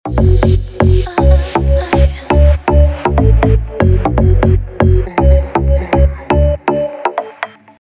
Synth Pop
Electronic
Cover
чувственные
Tech House